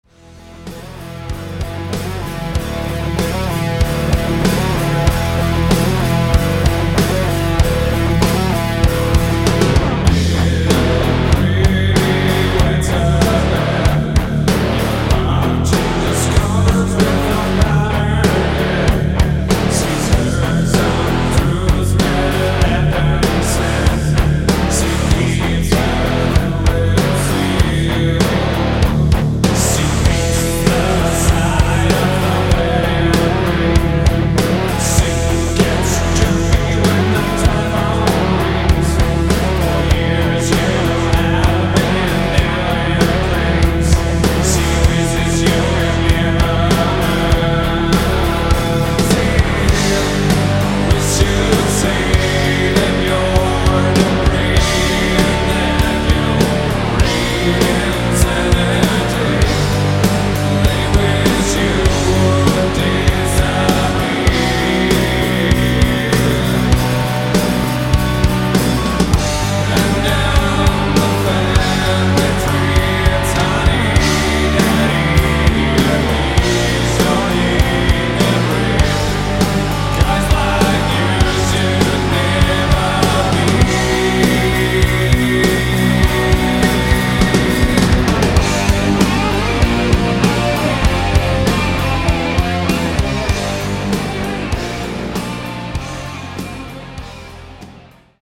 The music is best described as Gothic/wave.